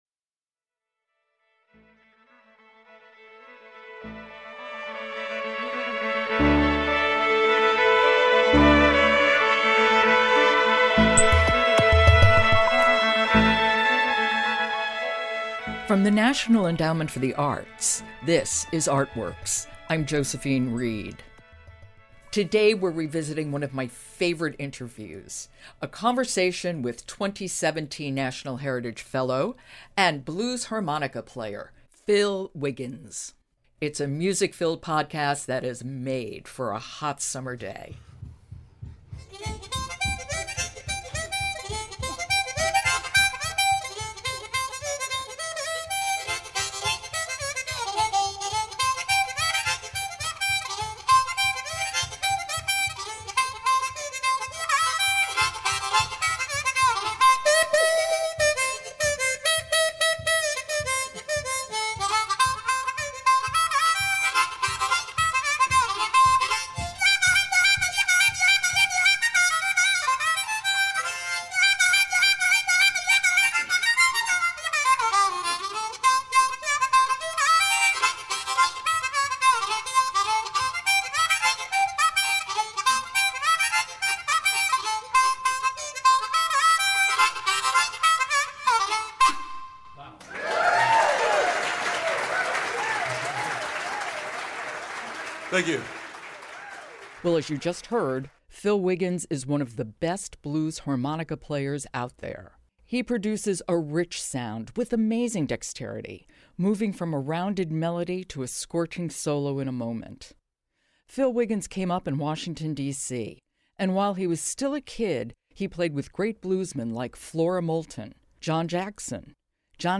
Harmonica Player and 2017 National Heritage Fellow Phil Wiggins talks about -and demonstrates- playing the Blues.